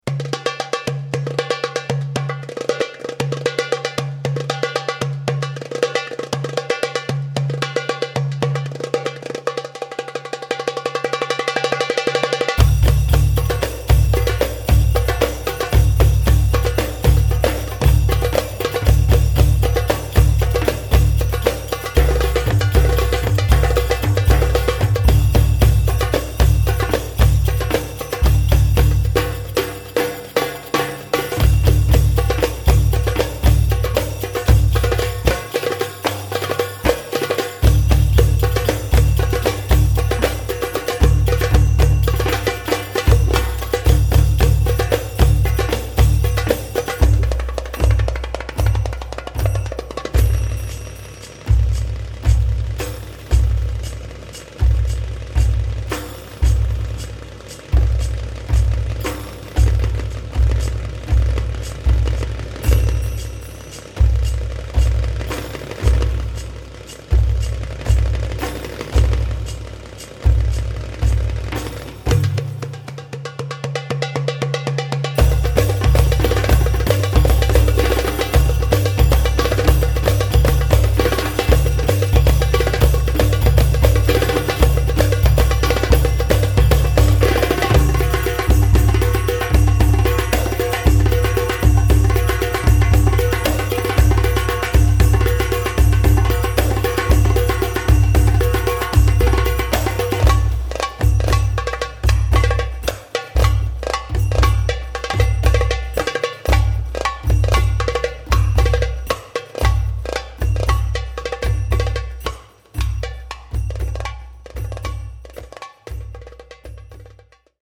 middle-eastern drums: